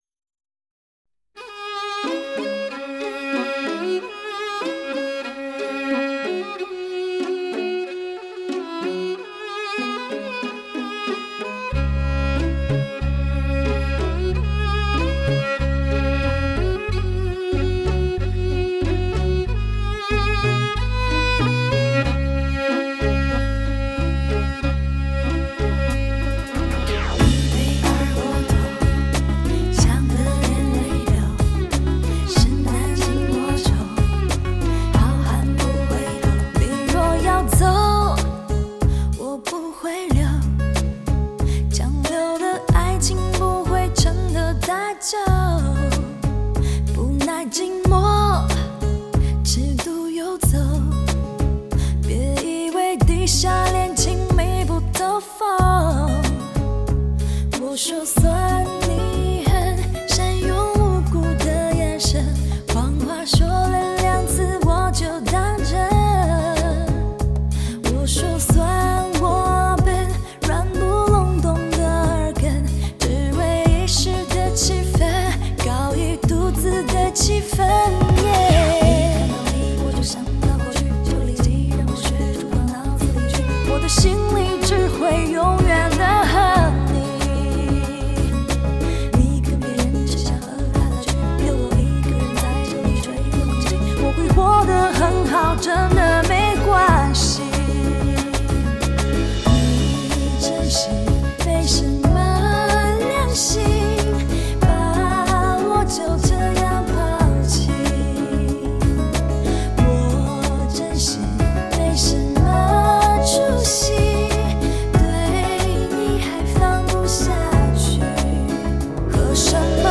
清纯，通透，充满磁性，天使般空灵的真空管女声。
真空管录音极品，将声音带到另一个境界，如亲临音乐厅欣赏音乐会一般。